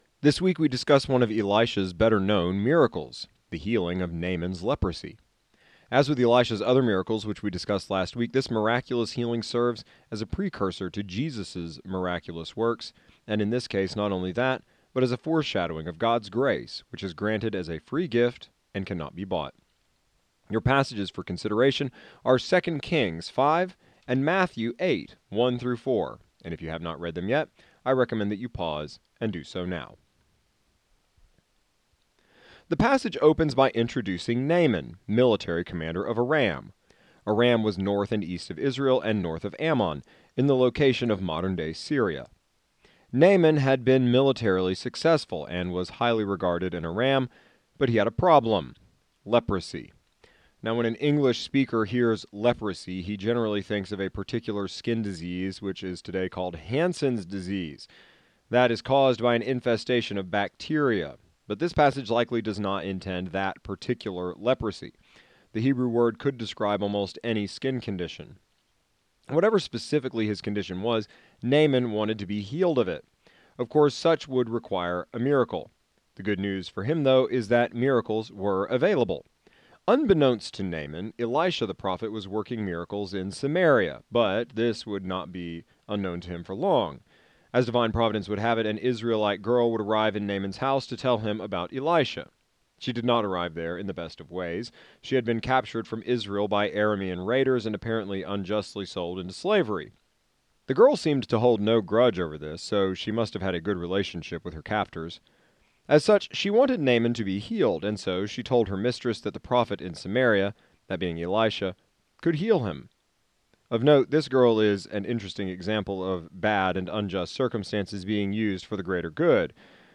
Jail Sermon Collection Transcript Unavailable